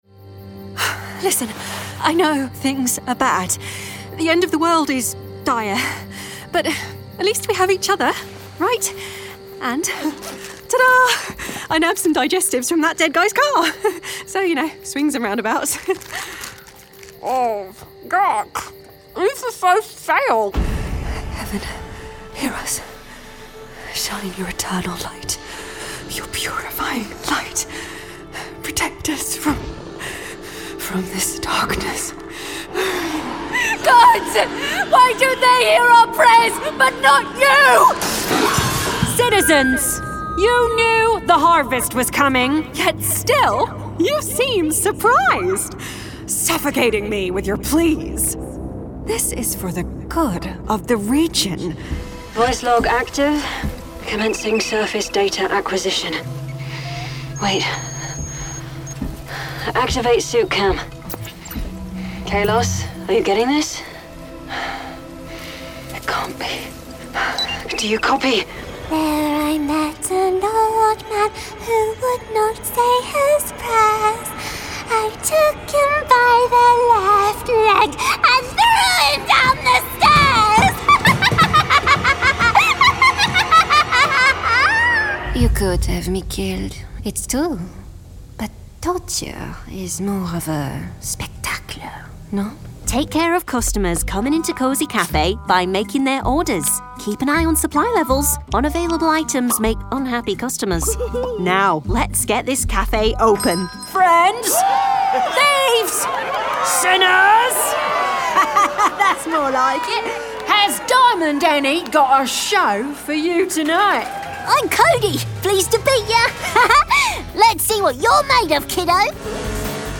Video Game Showreel
Female
British RP
American Standard
Bright
Friendly
Playful
Youthful